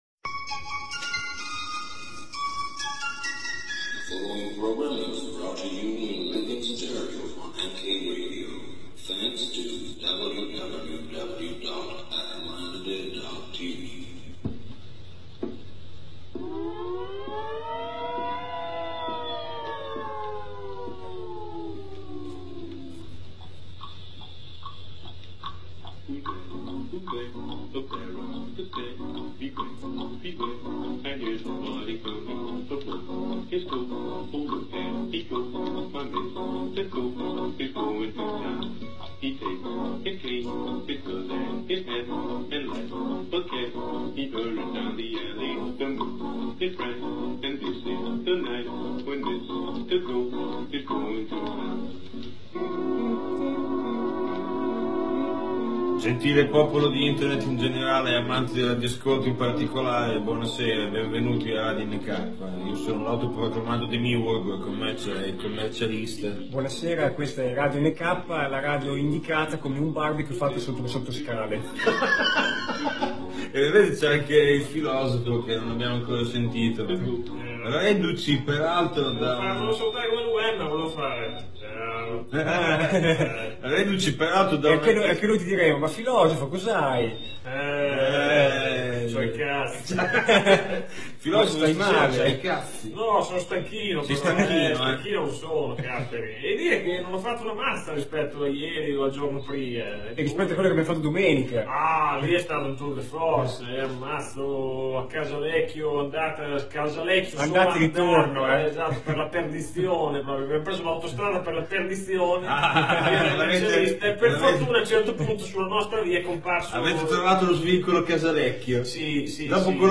Puntata, come si diceva, registrata con un accrocchio, anche se alla fine l’intellegibilità è salva.